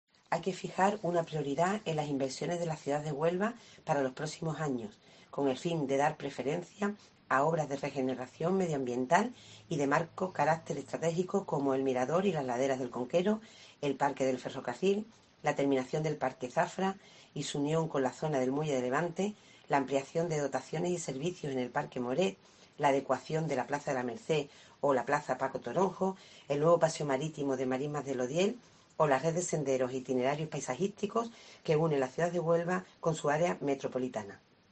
Pilar Marín, presidenta del Grupo Popular en el Ayto. Huelva